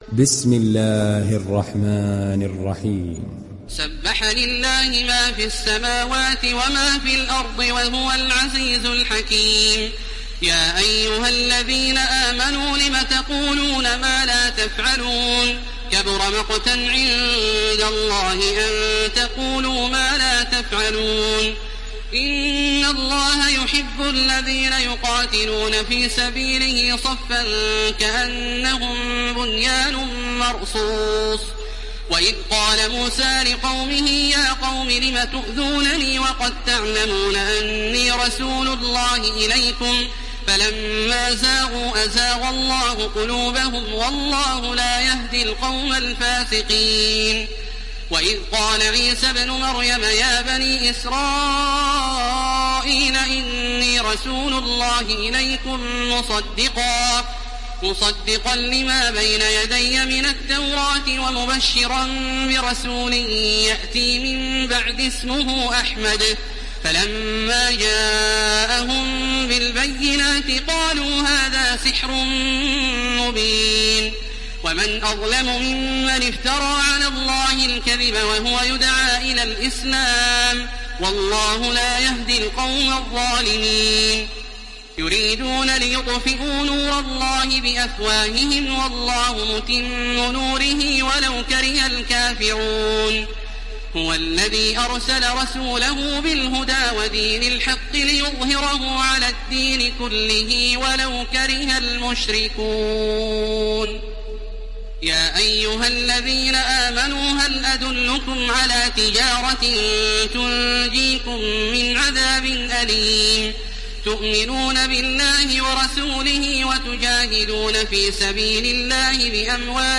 دانلود سوره الصف mp3 تراويح الحرم المكي 1430 روایت حفص از عاصم, قرآن را دانلود کنید و گوش کن mp3 ، لینک مستقیم کامل
دانلود سوره الصف تراويح الحرم المكي 1430